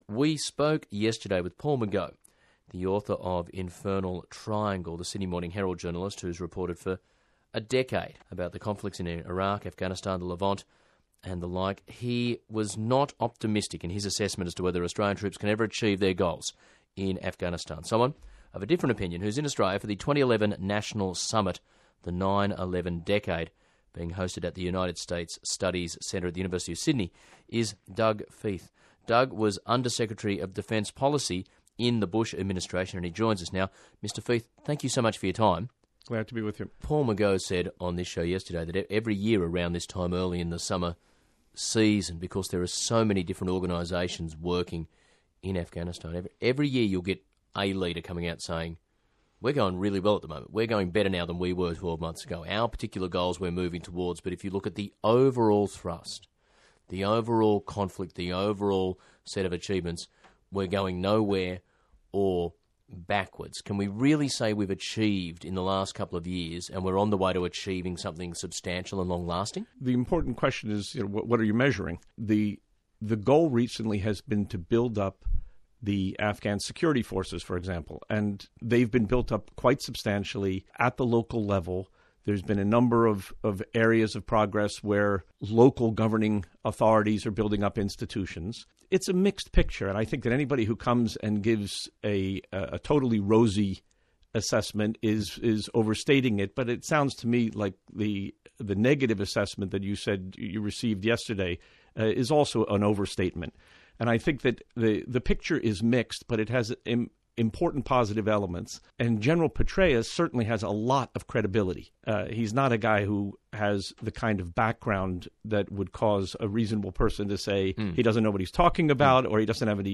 Appearance on Bill Bennett's Morning in America